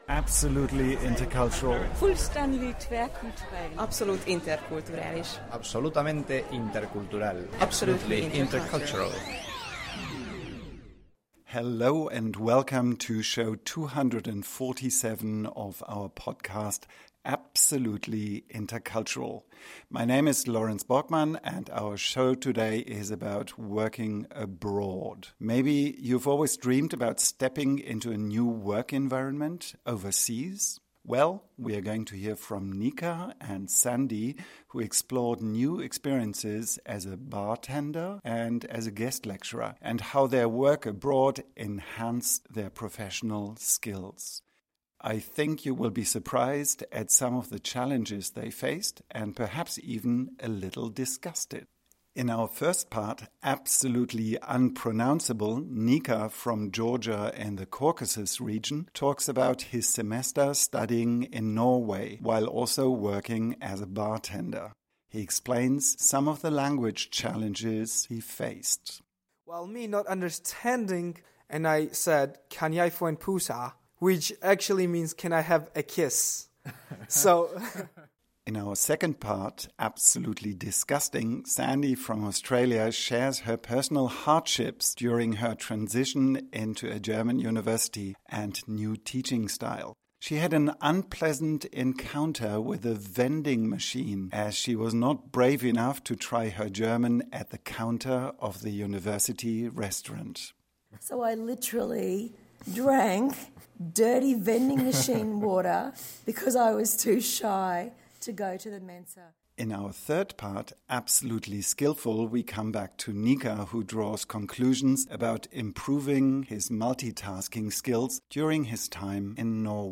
Our interviewer